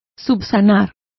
Complete with pronunciation of the translation of retrieve.